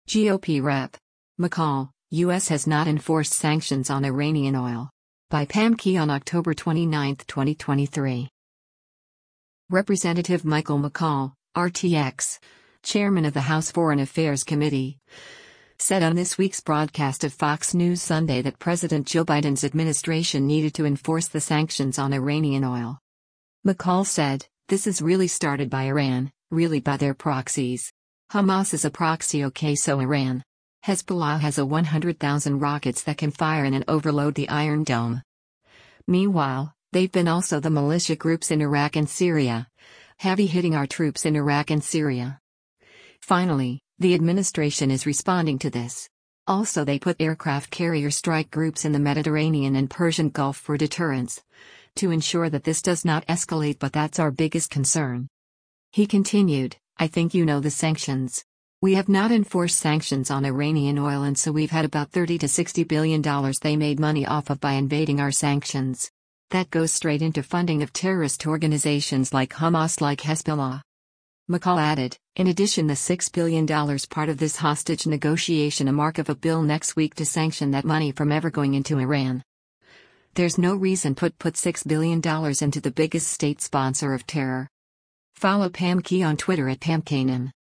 Representative Michael McCaul (R-TX), chairman of the House Foreign Affairs Committee,  said on this week’s broadcast of “Fox News Sunday” that  President Joe Biden’s administration needed to enforce the sanctions on Iranian oil.